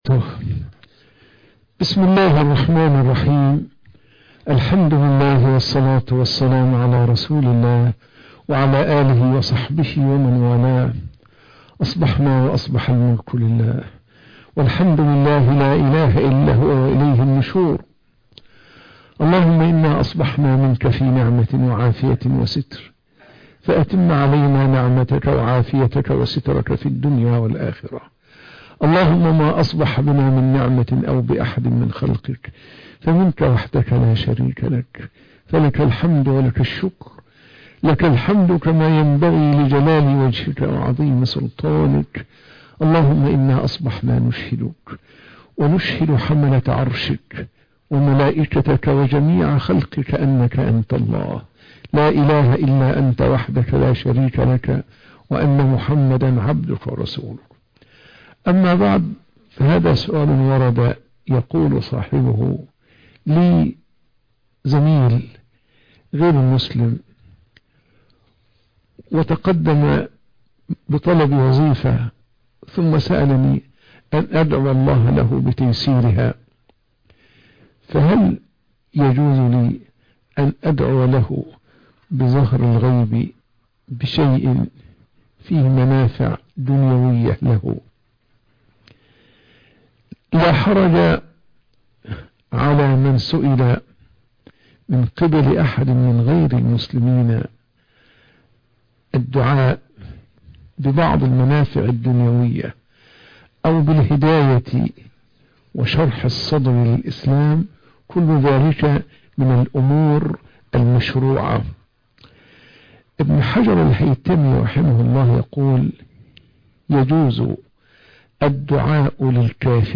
الدعاء لغير المسلم في حاجات الدنيا - درس بعد الفجر